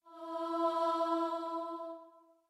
starting_note.mp3